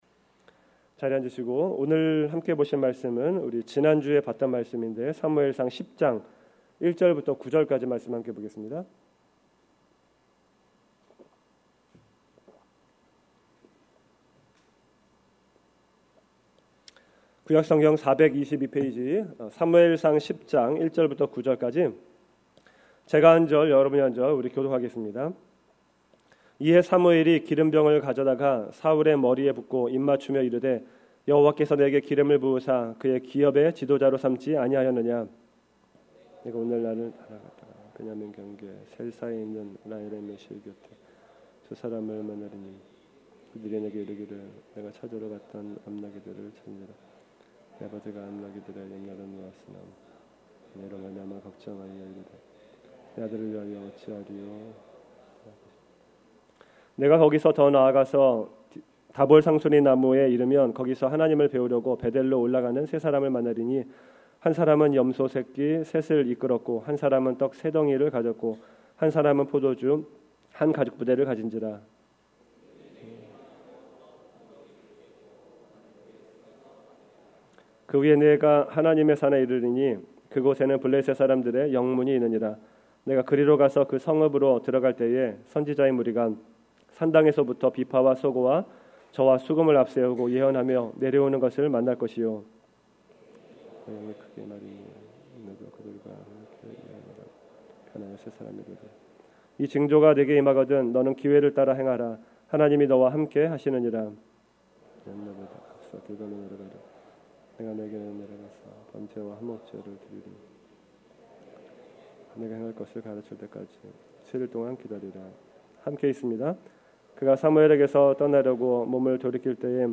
금요설교